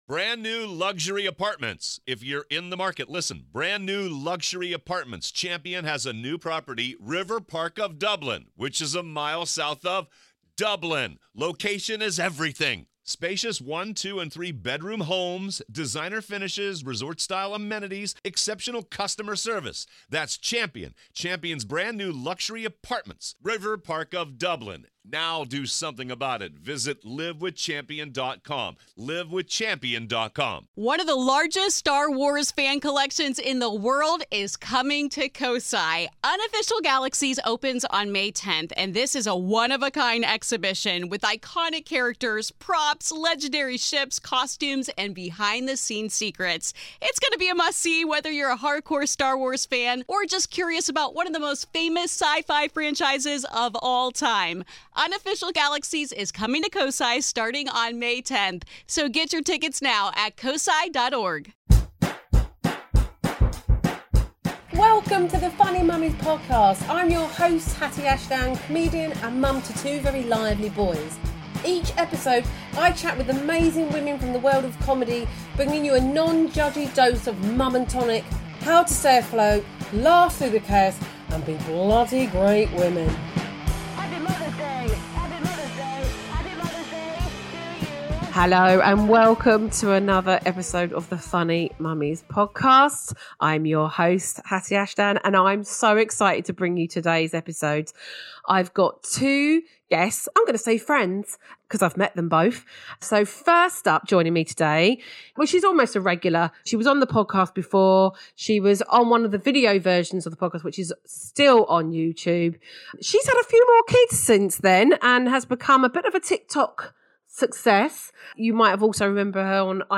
This episode features two incredible guests: